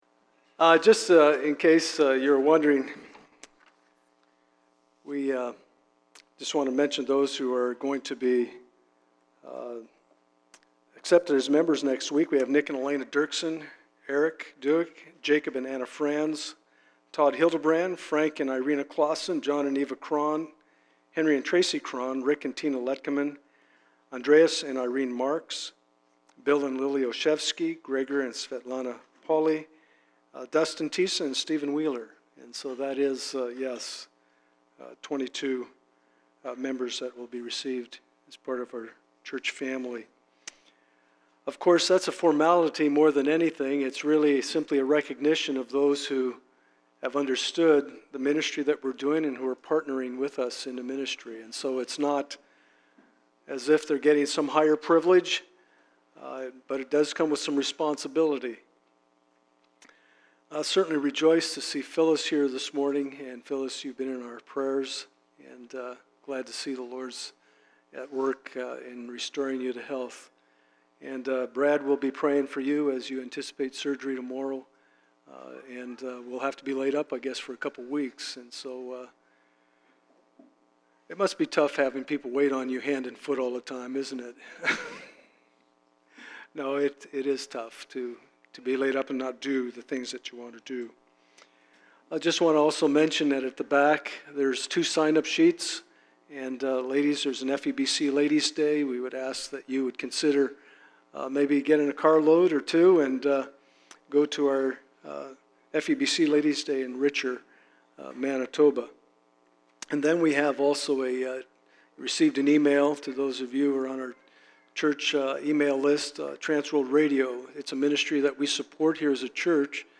Psalms Passage: 2 Samuel 15:1-13, Psalm 3:1-8 Service Type: Sunday Morning « Communion Service Psalm 4